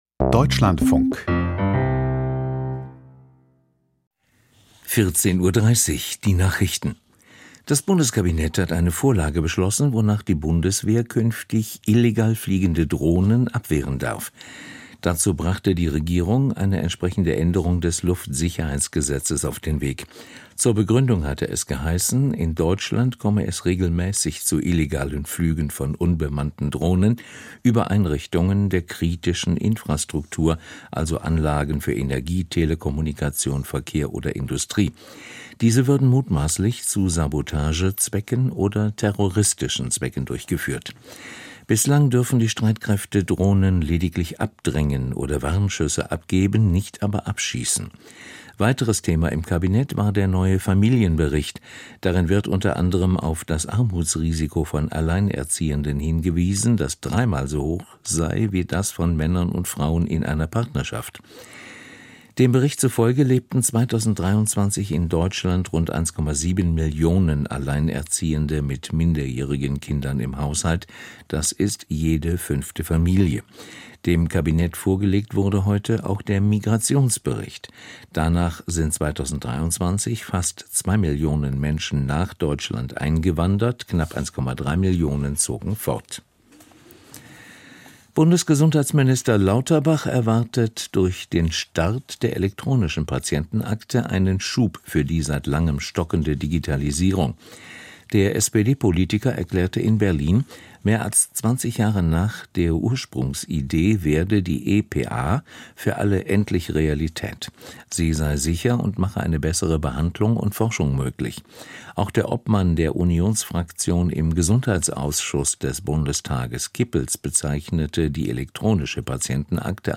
Die Deutschlandfunk-Nachrichten vom 15.01.2025, 14:30 Uhr